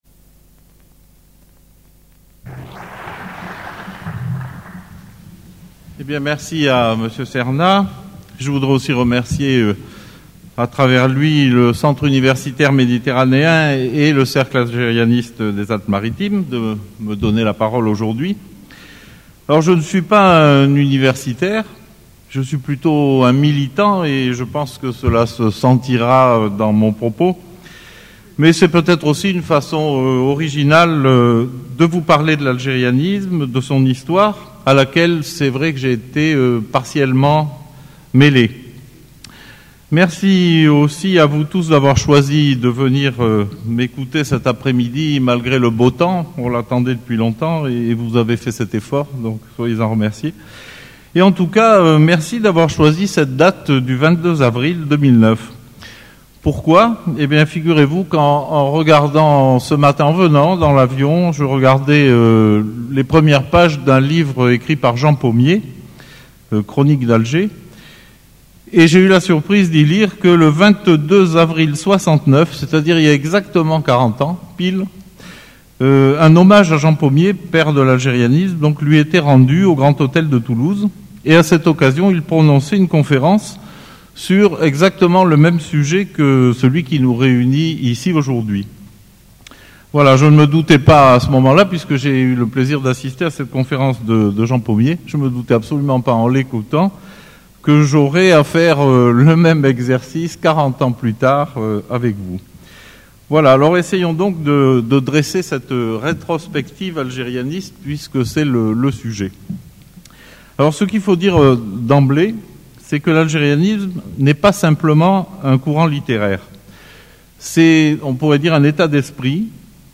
Les trois premières minutes de la conférence...
Le mercredi 22 avril 2009, la Chaire Algérianiste accueillait au Centre Universitaire Méditerranéen un des pères fondateurs du renouveau de ce mouvement culturel né au début du siècle à Alger et renaissant, tel le Phénix, en 1973 en France, l'Algérianisme.